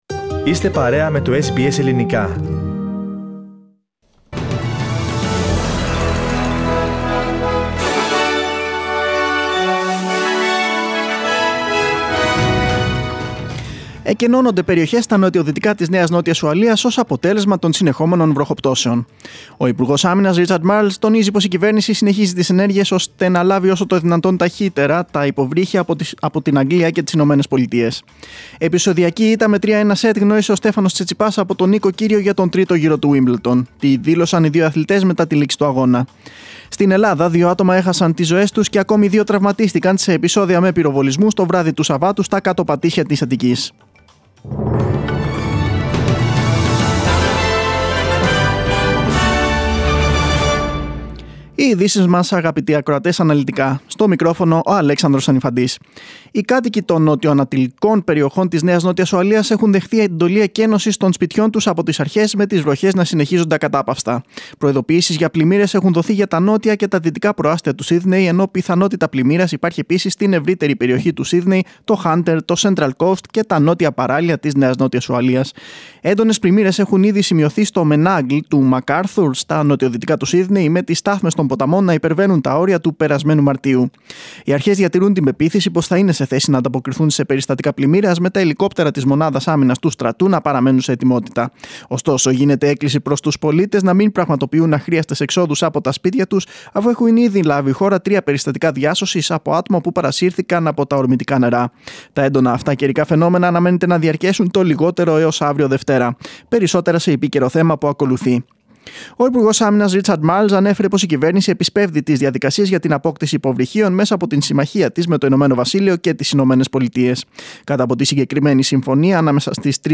Δελτίο Ειδήσεων 3.7.2022
News in Greek. Source: SBS Radio